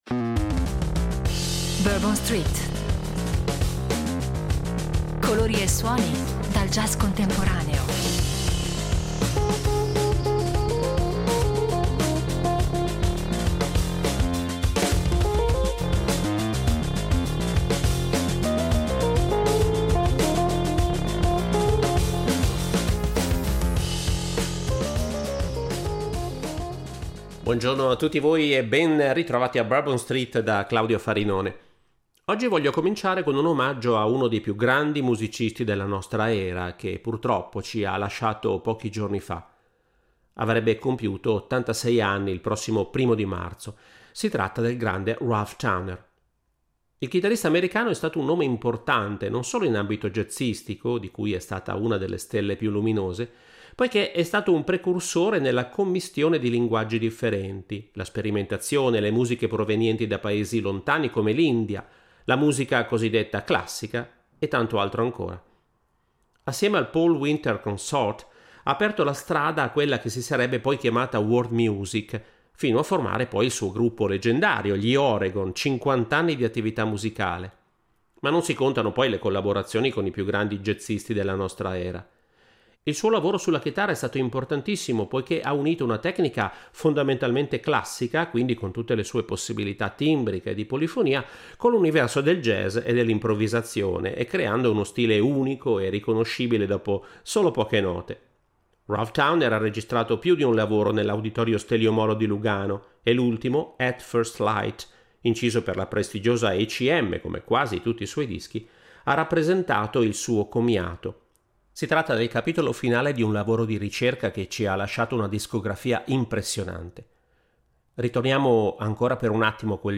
E altri percorsi dal sapore jazz